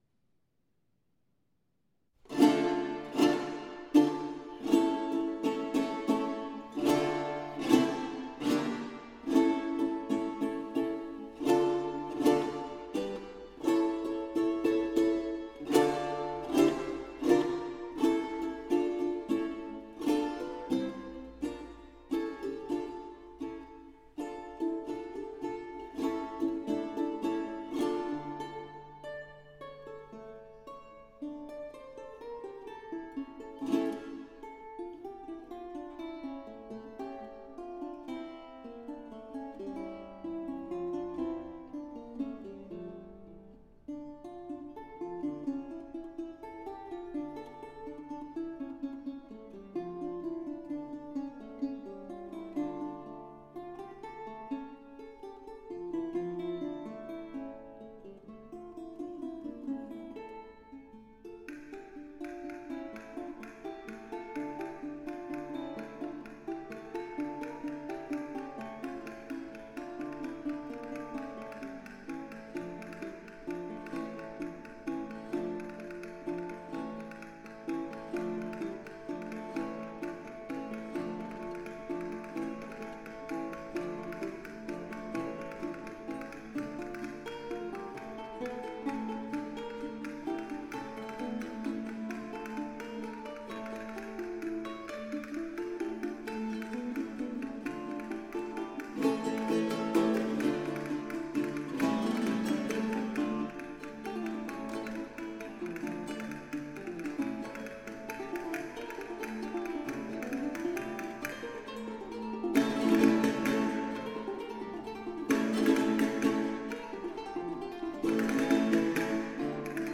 In the fandango, harmonies alternate between chords i-V-i-V. The phrases end suspended on the dominant harmony, rhythms are fast in triple meter, and chords never come to a cadential close.